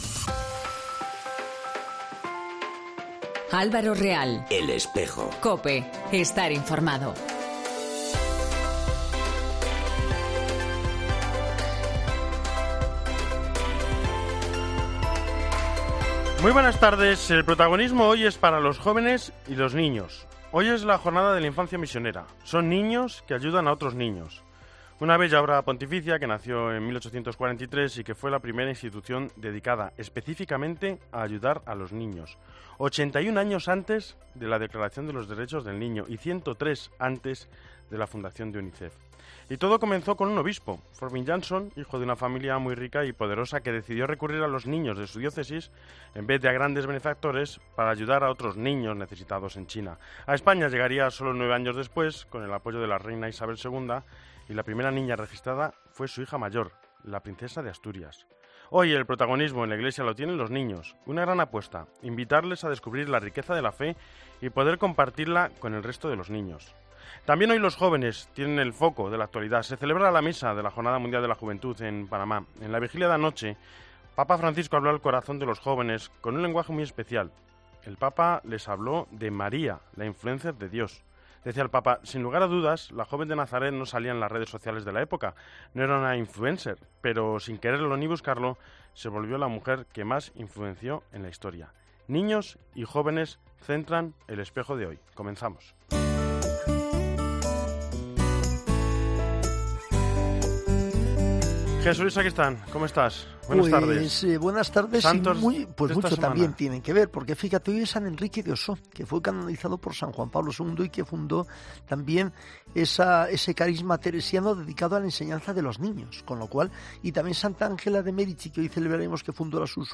entrevista JMJ y vida de Hispanoamérica.